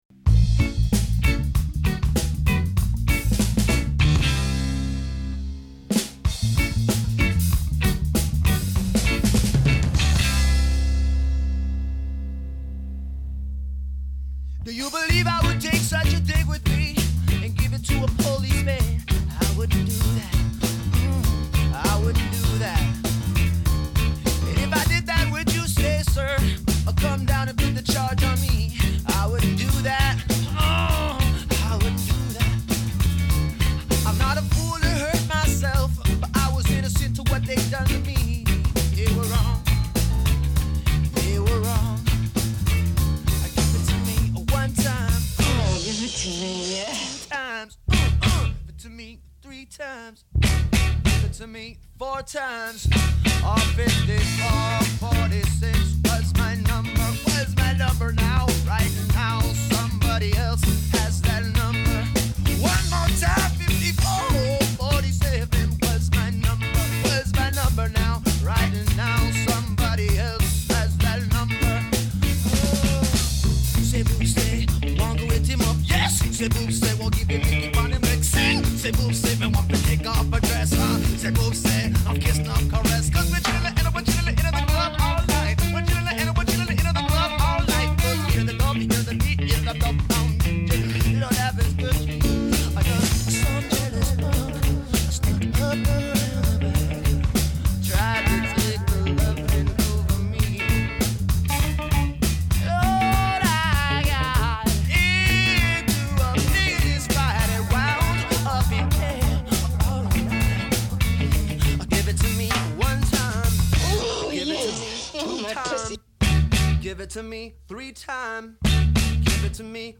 a great grooving medley